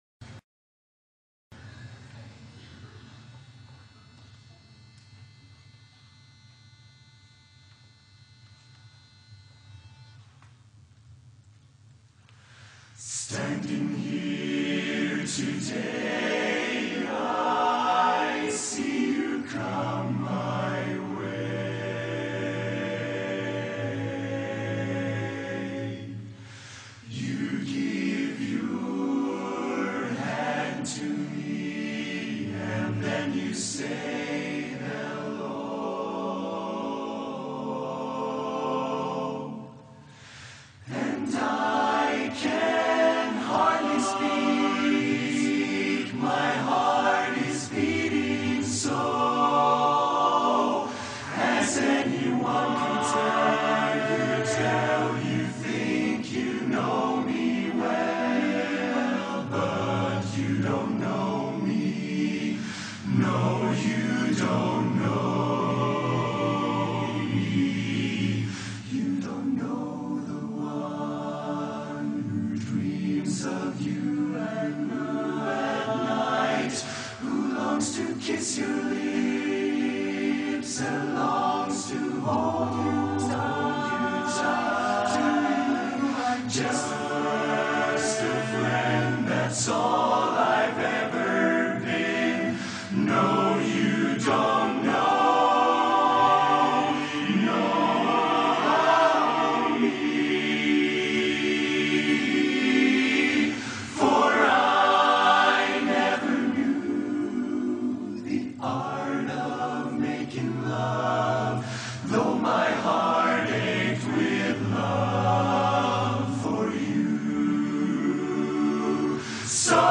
Harmony ReChoired (chorus)
Ballad
Barbershop
D Major